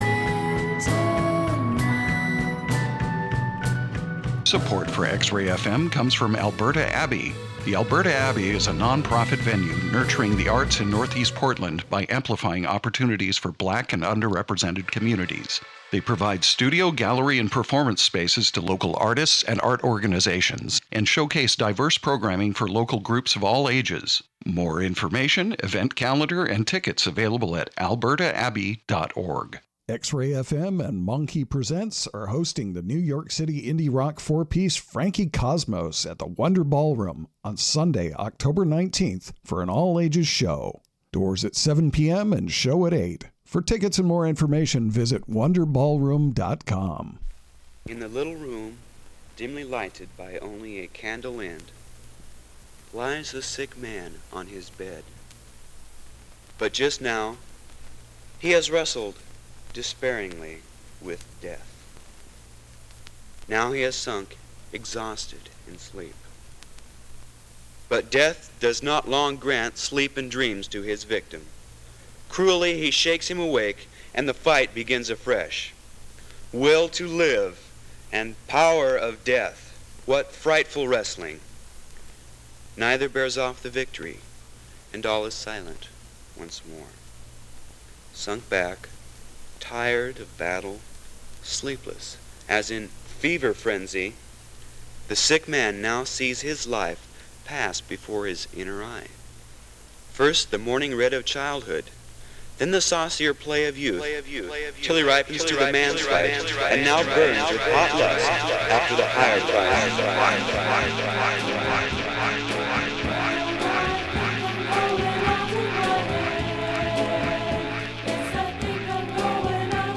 All original vinyl, from all over the world.